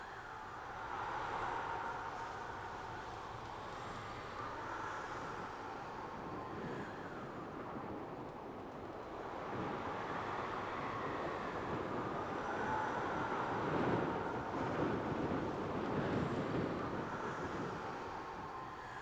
WAV · 1.6 MB · 立體聲 (2ch)